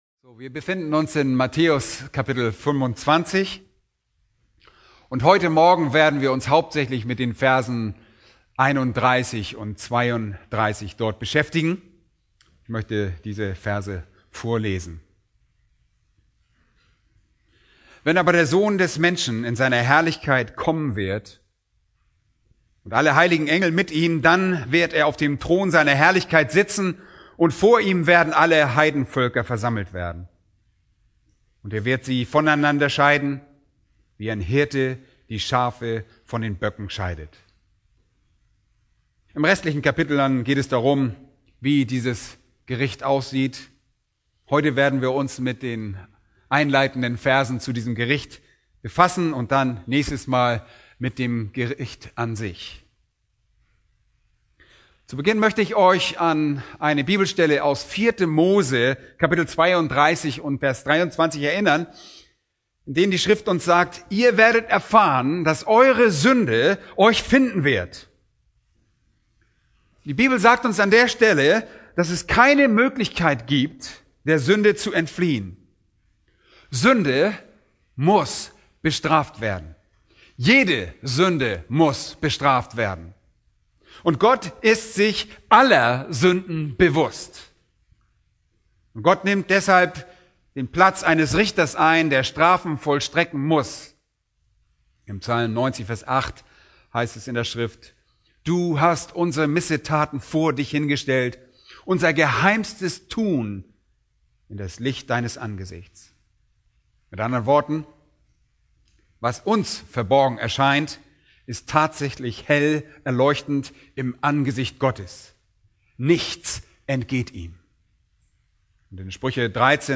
Eine predigt aus der serie "Markus." Das Gleichnis vom Ackerboden (6)